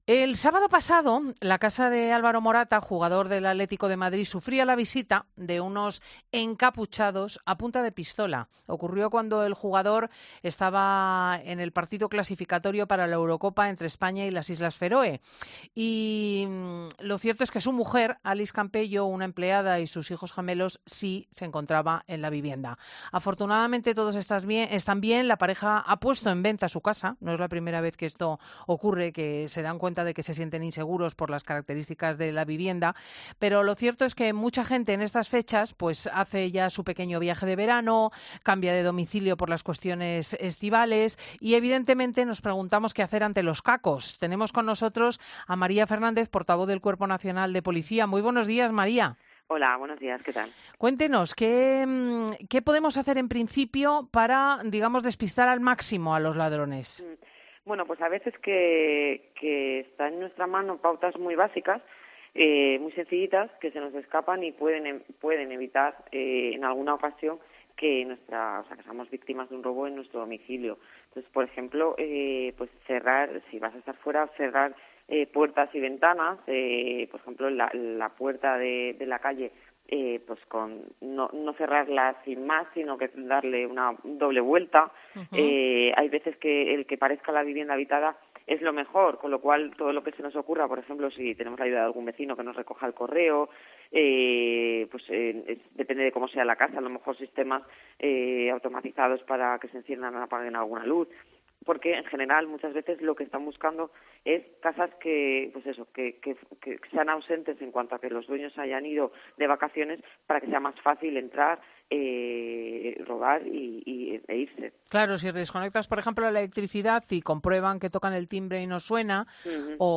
Repasamos con la Policía cómo evitar que un susto arruine nuestras vacaciones